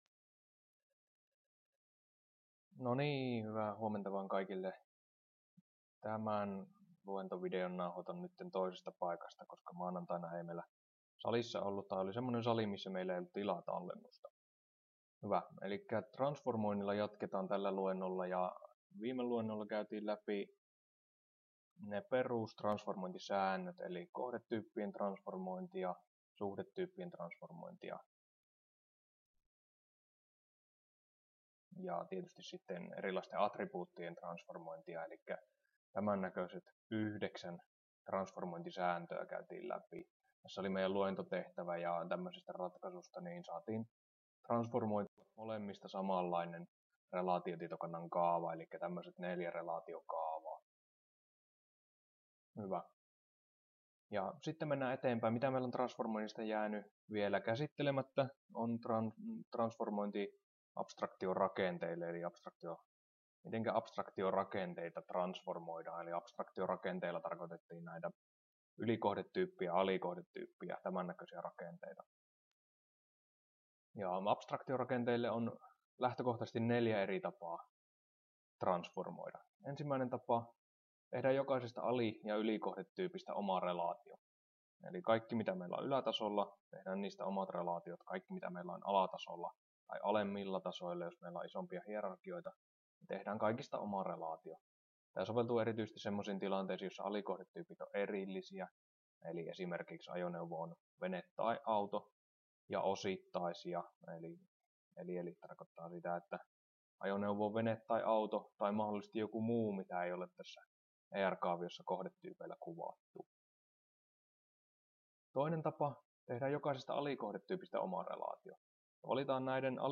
Luento 5 osa 1 — Moniviestin